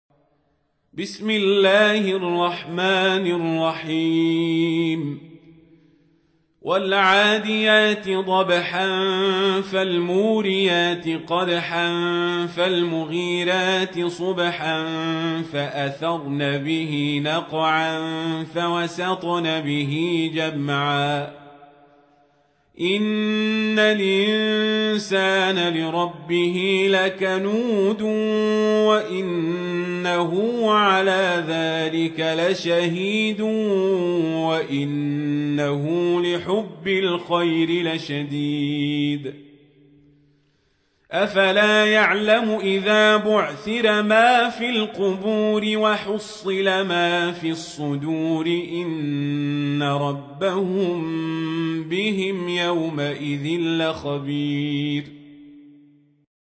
سورة العاديات | القارئ عمر القزابري